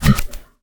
Minecraft Version Minecraft Version snapshot Latest Release | Latest Snapshot snapshot / assets / minecraft / sounds / mob / camel / dash_ready1.ogg Compare With Compare With Latest Release | Latest Snapshot
dash_ready1.ogg